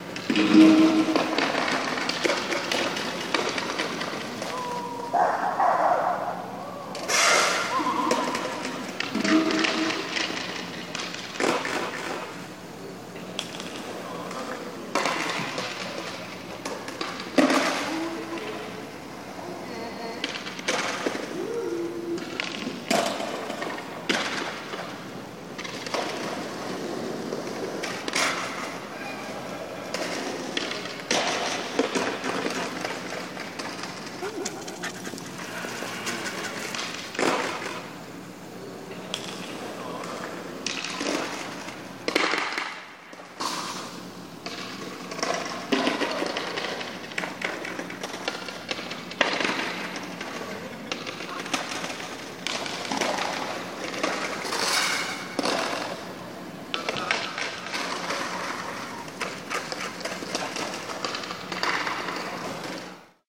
Skateboarding on the steps outside Birkbeck University, Torrington Square - remix
Remix of recording in Bloomsbury, central London, 26th October 2014.